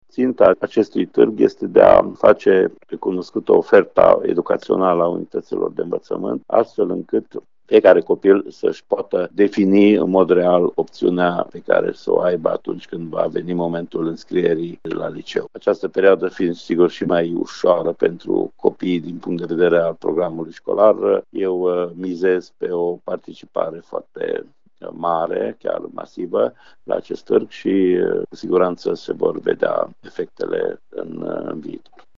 Inspectorul școlar general al județului Mureș, Ștefan Someșan.